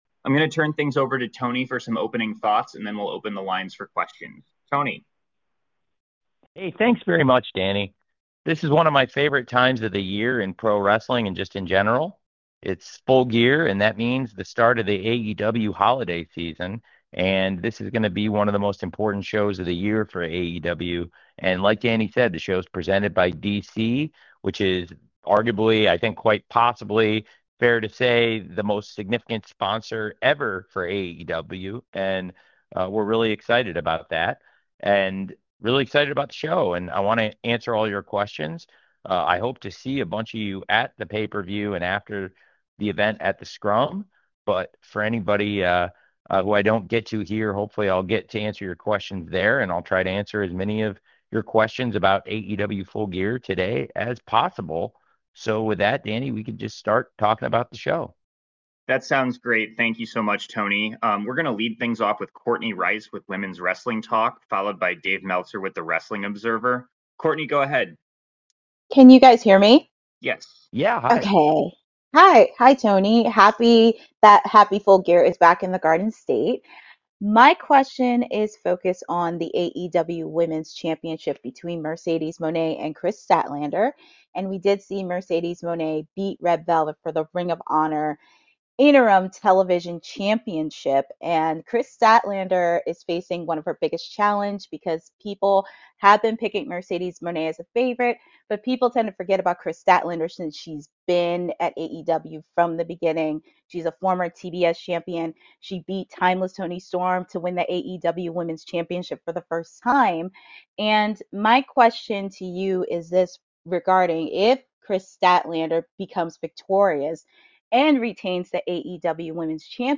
AEW head Tony Khan spoke to the media for an hour Thursday ahead of Saturday’s Full Gear.